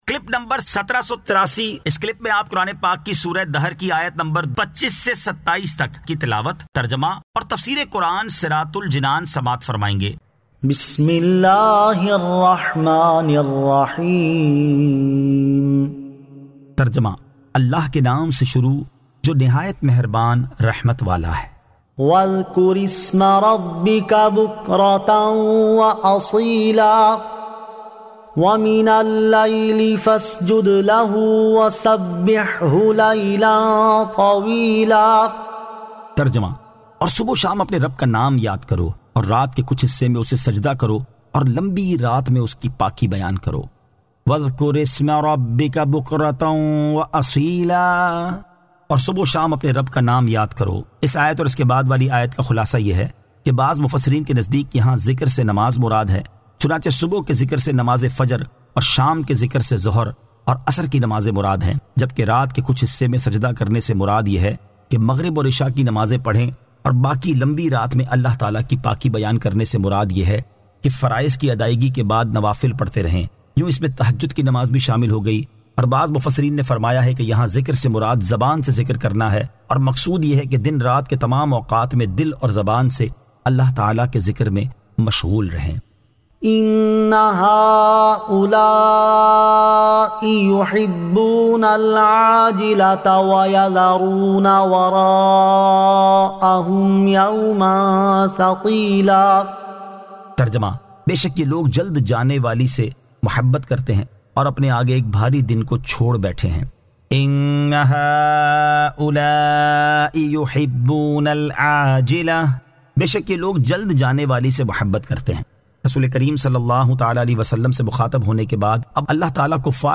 Surah Ad-Dahr 25 To 27 Tilawat , Tarjama , Tafseer